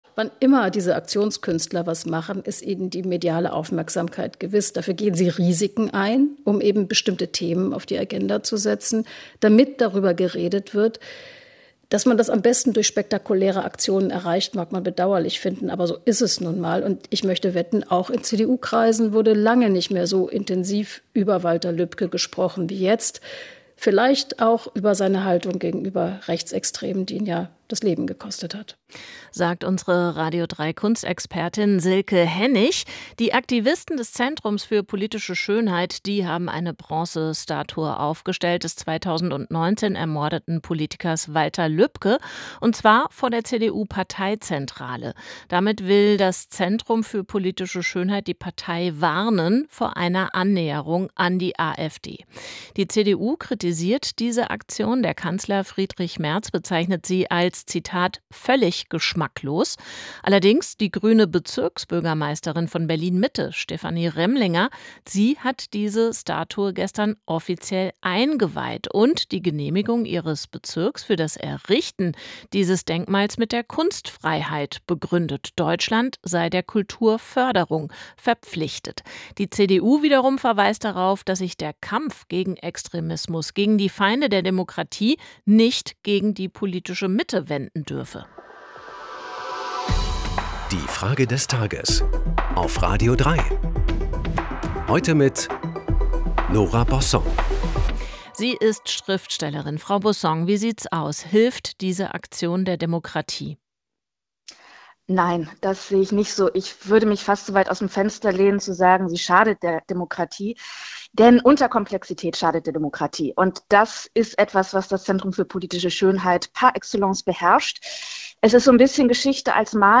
Schriftstellerin Nora Bossong.